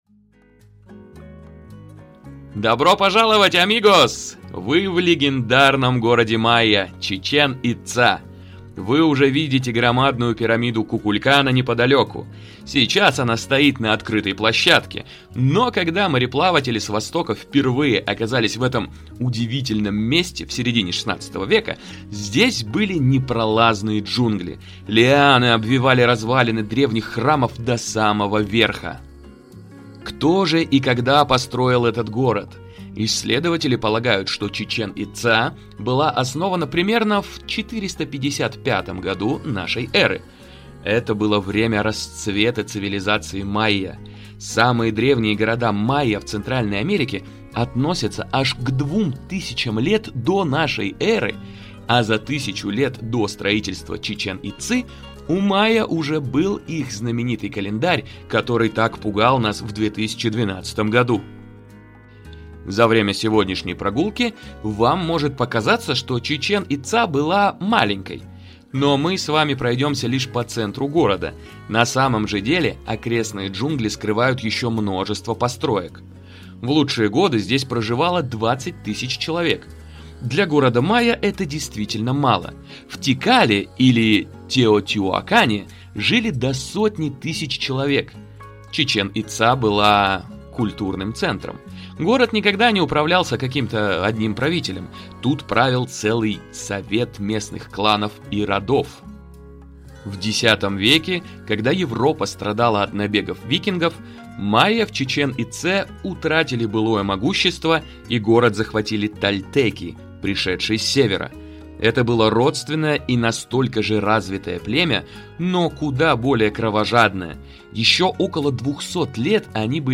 TouringBee приглашает вас в Мексику - исследовать древний город Чичен-Ица с авторским аудиогидом, что раскроет тайны майя.